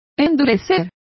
Complete with pronunciation of the translation of toughening.